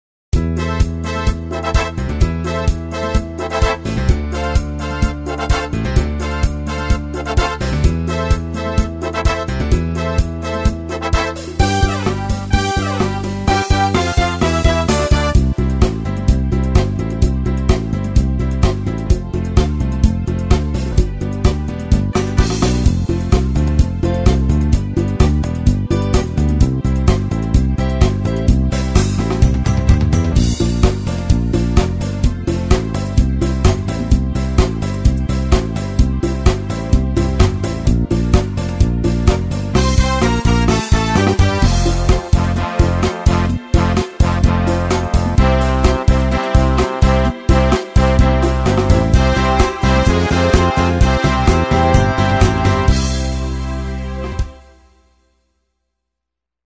schöner Discofox-Rhythmus